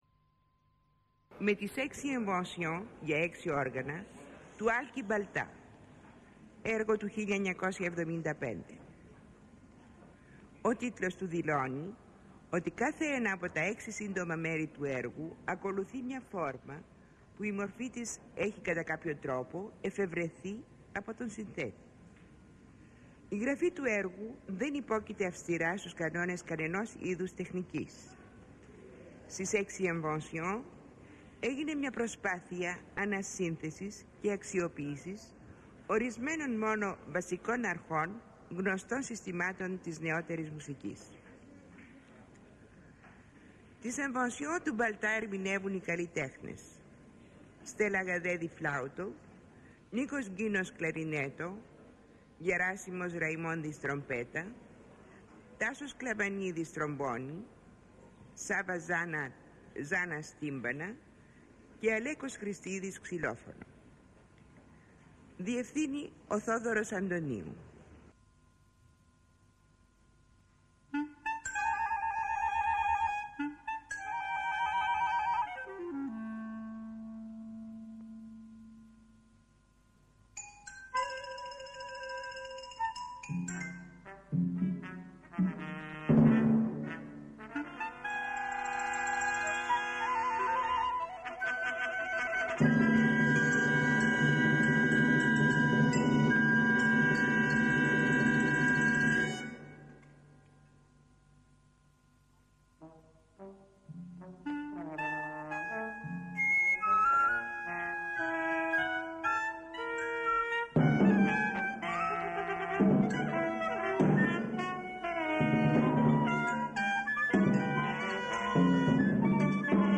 φλάουτο
κλαρινέτο
τρομπέτα
τρομπόνι
τύμπανα
ξυλόφωνο